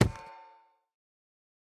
chiseled_bookshelf
insert_enchanted1.ogg